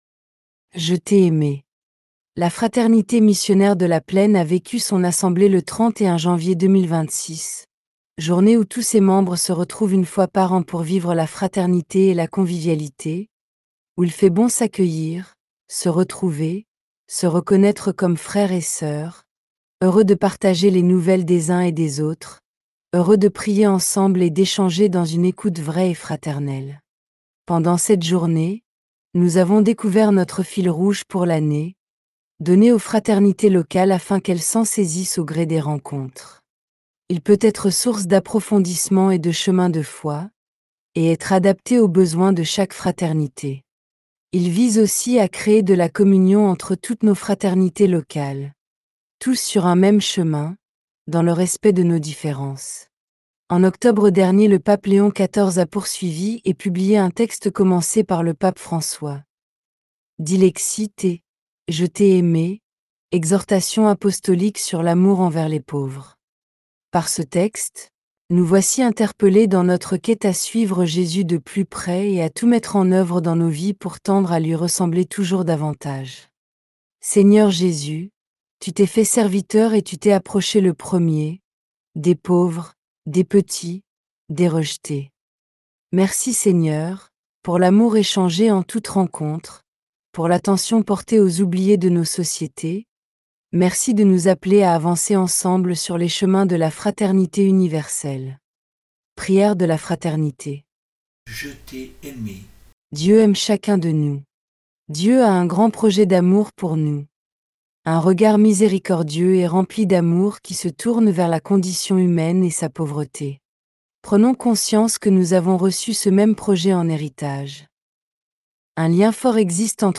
*  Voix de synthèse